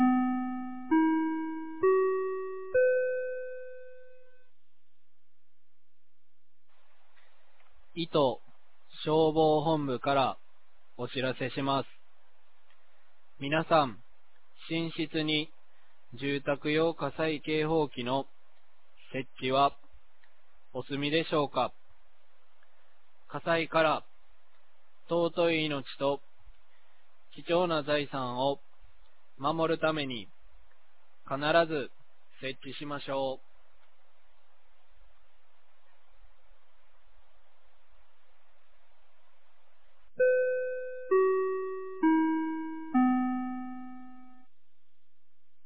2024年09月17日 10時00分に、九度山町より全地区へ放送がありました。
放送音声